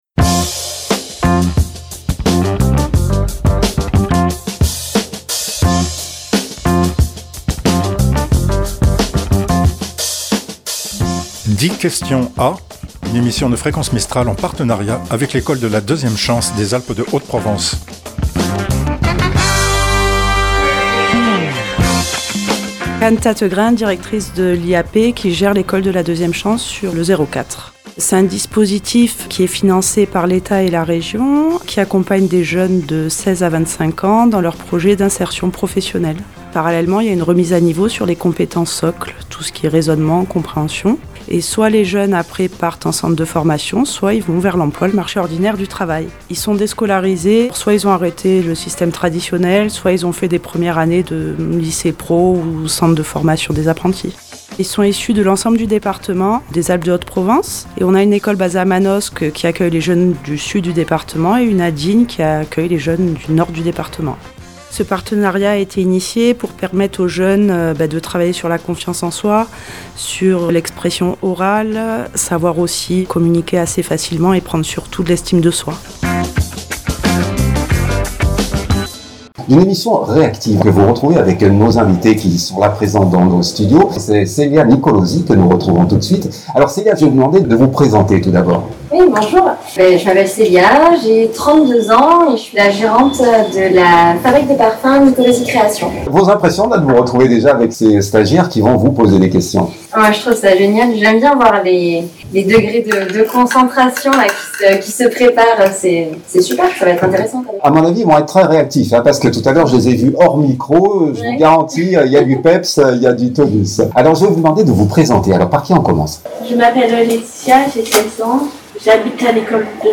Suite à un incident technique, la qualité du son a été affectée. Veuillez nous excuser de ce désagrément